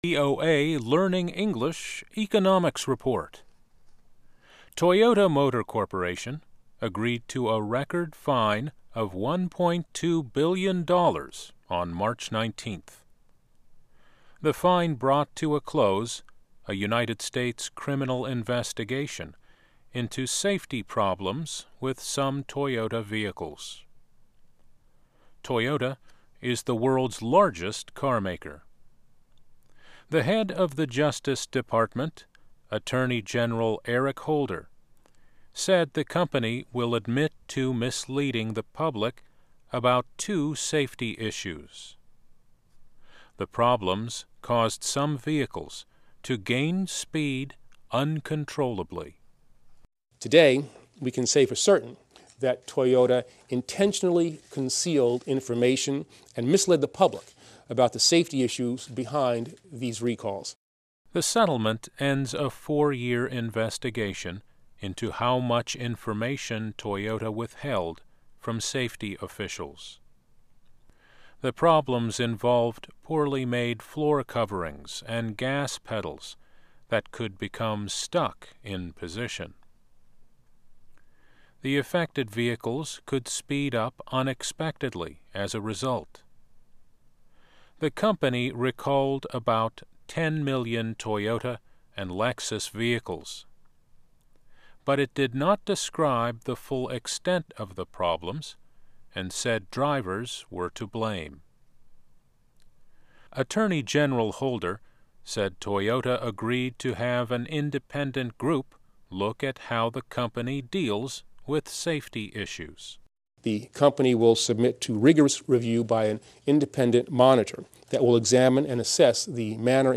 Learn English as you read and listen to news and feature stories about business, finance and economics. Our daily stories are written at the intermediate and upper-beginner level and are read one-third slower than regular VOA English.